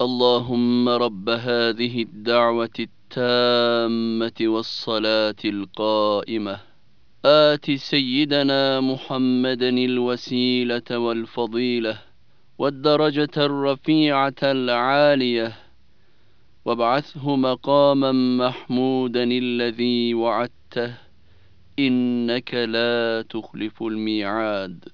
Ezan duası